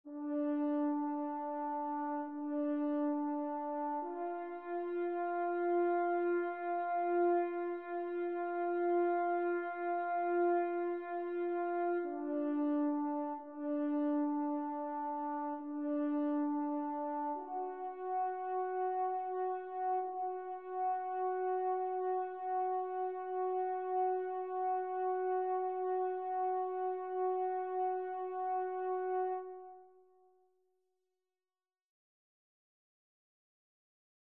Miroslav Philharmonik VSTi "French Horn" -- WAVE (6.1MB)
Cuivrissime-IV-MiroslavExtrait-MP-VSTi-Coronet.wav